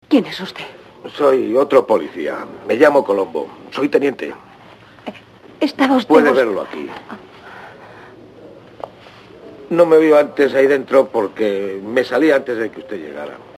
En formato también monoaural, este sonido es correcto, con una fidelidad y calidad similar a la de la pista en inglés.
Es interesante destacar que la voz de Colombo en los dos primeros episodios regulares (ambos se encuentran en el primer disco) es claramente diferente a la del resto de episodios, como puede escucharse al comparar
la primera voz (extracto del primer episodio del primer disco) con